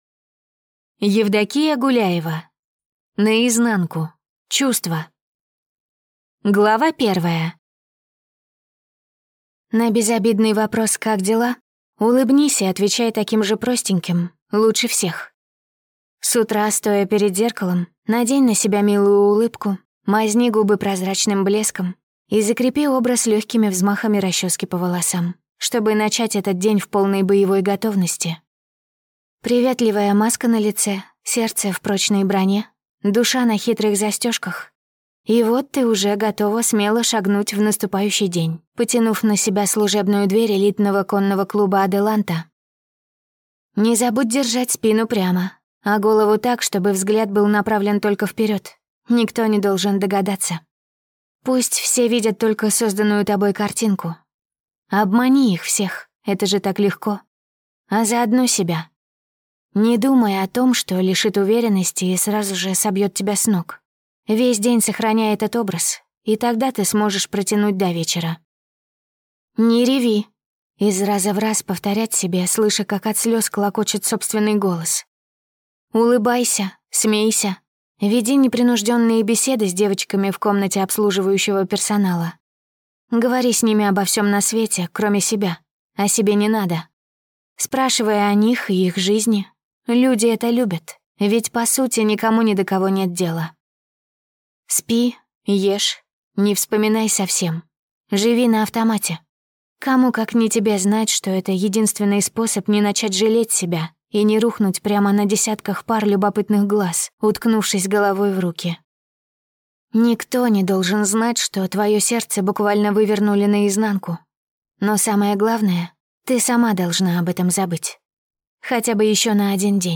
Аудиокнига «Последний Страж. Том 1».